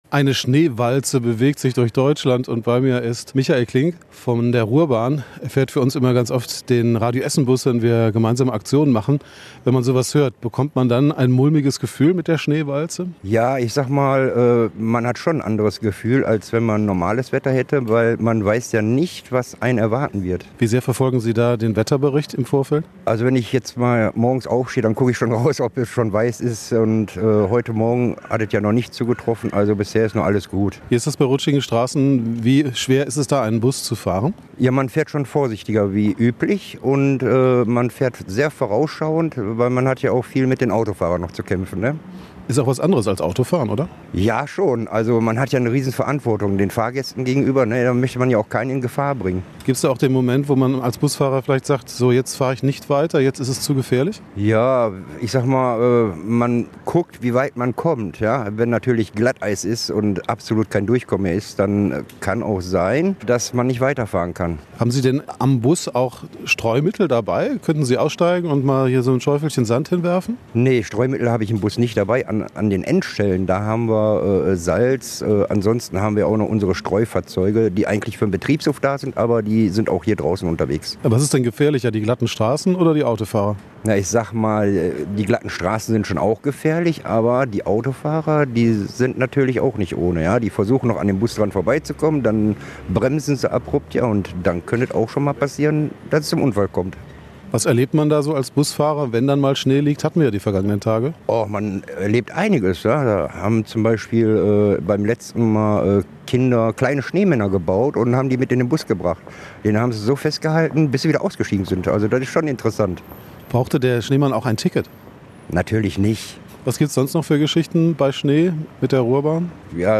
Darüber hinaus gibt es aber auch schöne Erlebnisse im Winter, berichtet ein Busfahrer der Ruhrbahn.